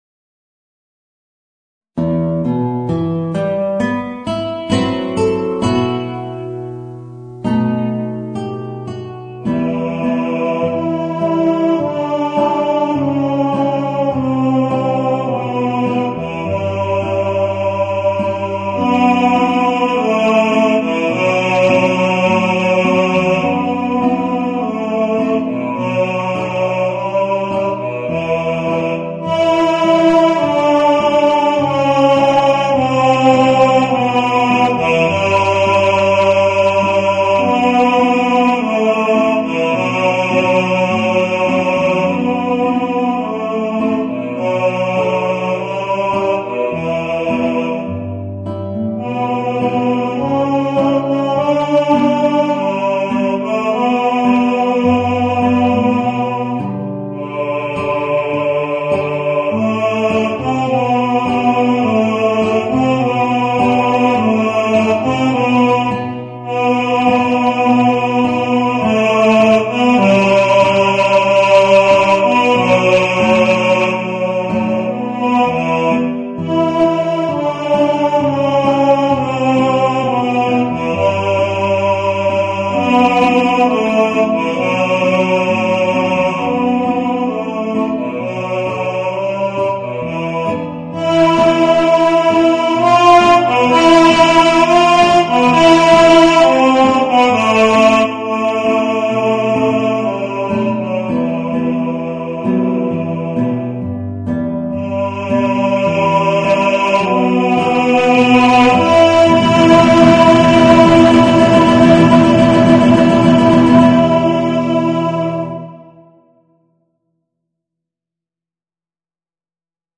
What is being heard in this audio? Voicing: Baritone